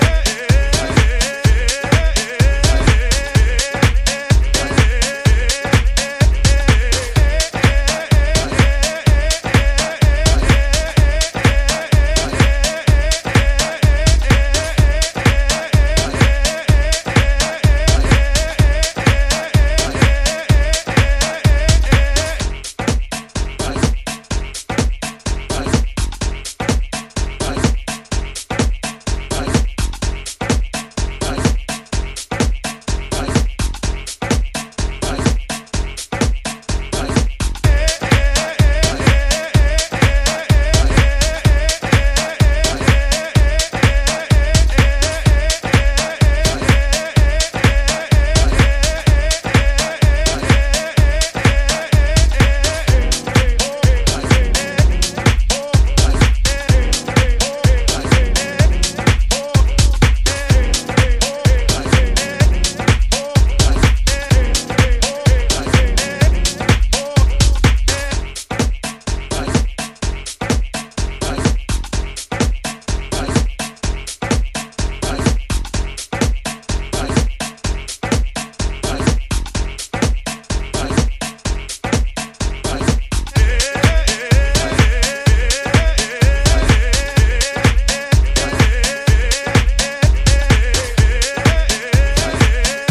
underground house excellence